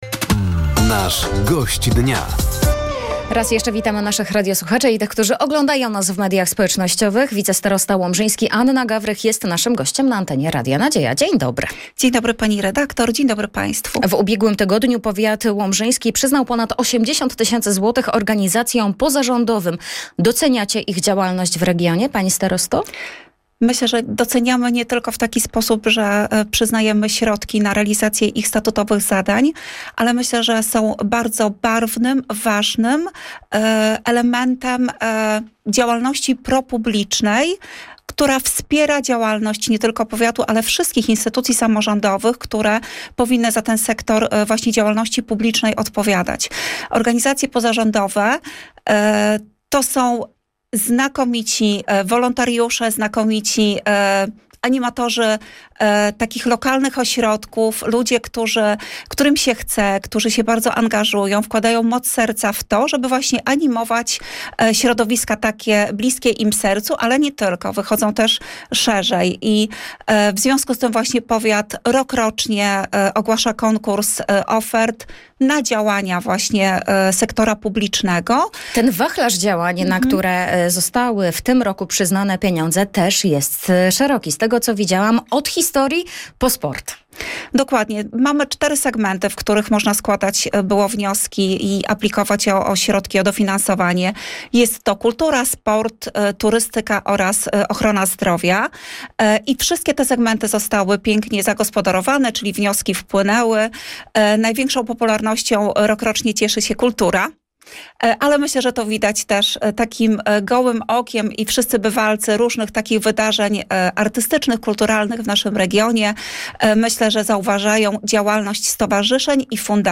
Zapraszamy do wysłuchania rozmowy z wicestarostą łomżyńskim, Anną Gawrych.